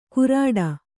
♪ kurāḍa